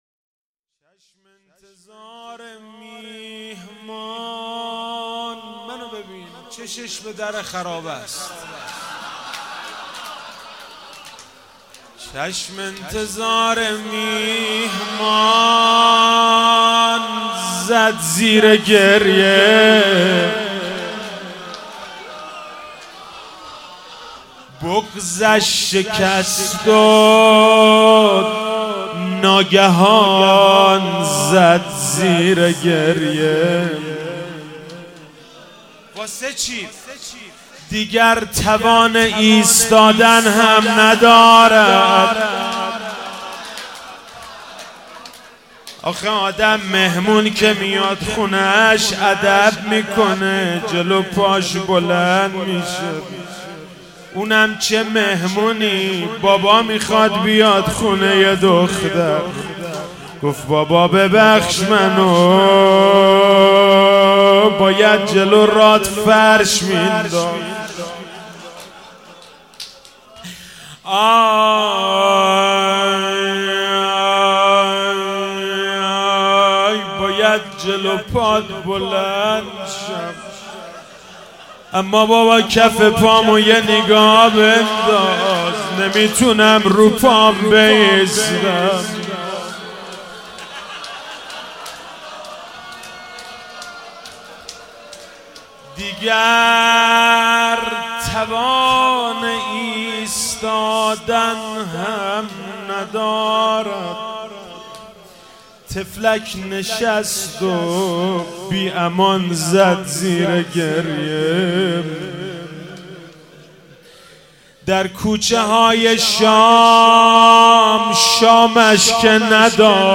ولادت حضرت رقیه (س) 98 - روضه - چشم انتظار مهمان زد زیر گریه